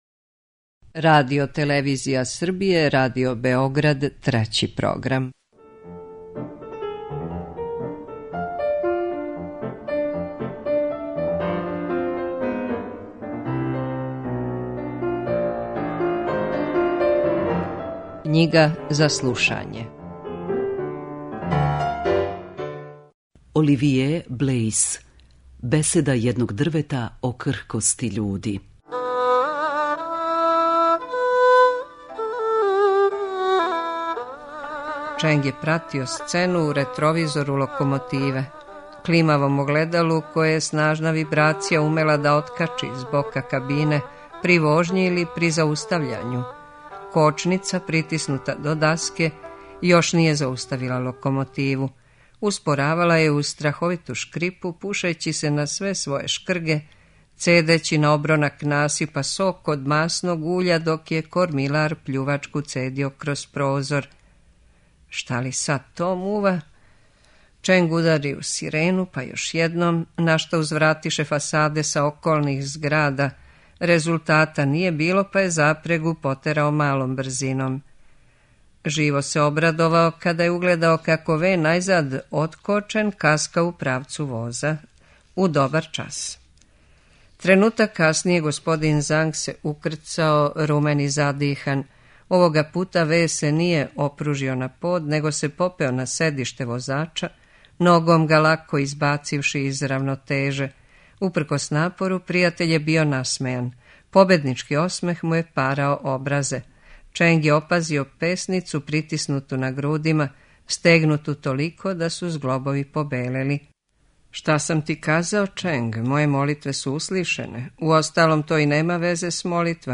преузми : 5.68 MB Књига за слушање Autor: Трећи програм Циклус „Књига за слушање” на програму је сваког дана, од 23.45 сати.